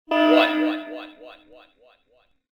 SelfDestructOne.wav